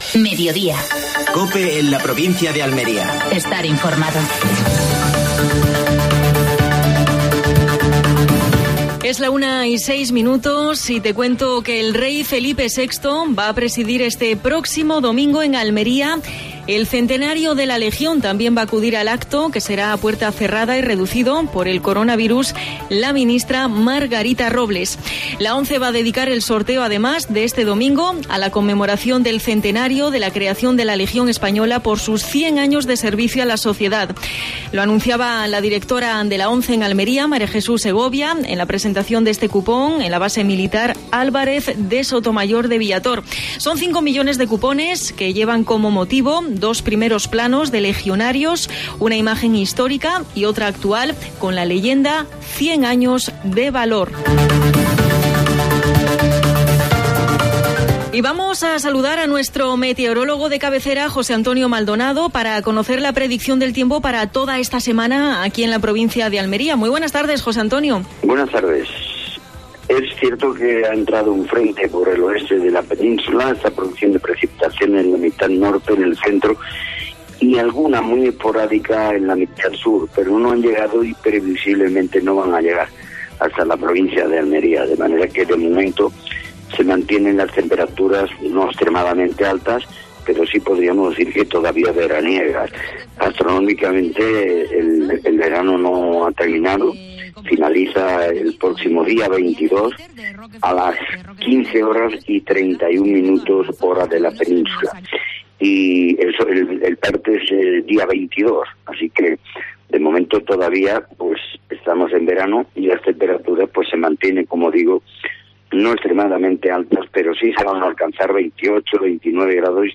AUDIO: Actualidad en Almería. Entrevista a Ramón Fernández-Pacheco (alcalde de Almería).